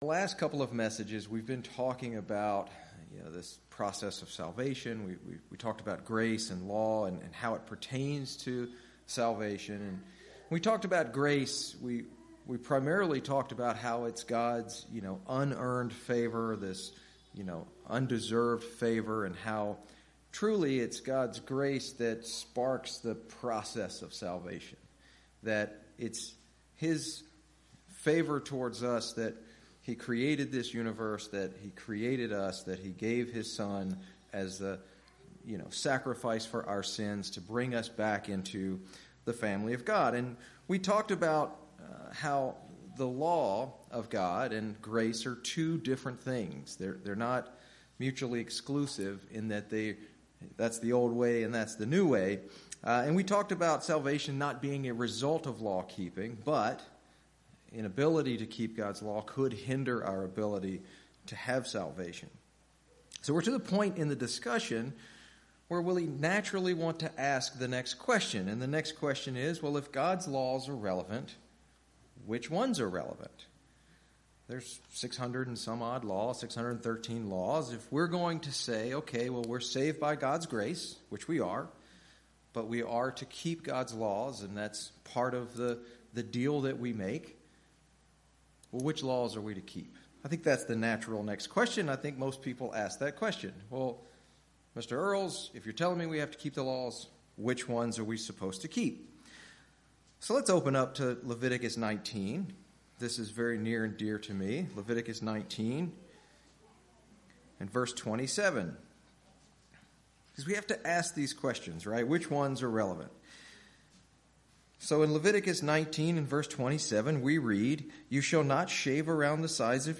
This series of sermons are designed to look at the basic building blocks of God’s plan of salvation. We will explore Grace, Law, and Love as it pertains to Salvation.